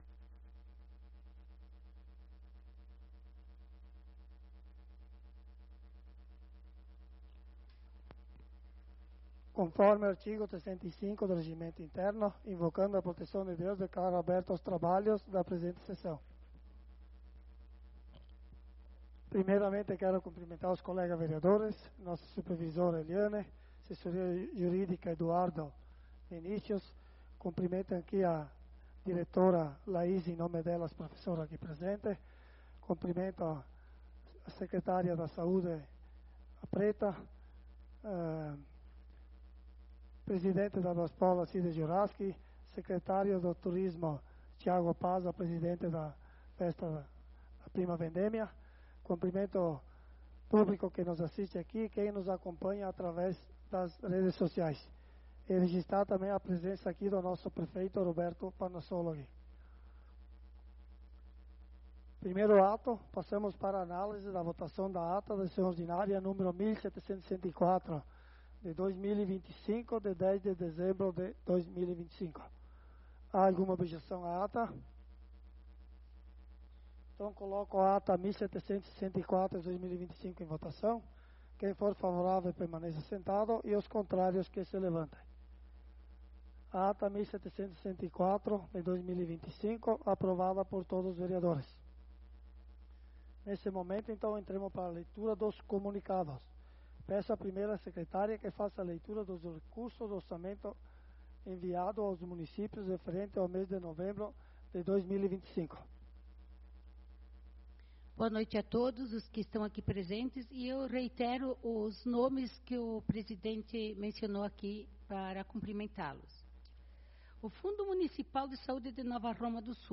Sessão Ordinária do dia 17/12/2025 - Câmara de Vereadores de Nova Roma do Sul
Sessão Ordinária do dia 17/12/2025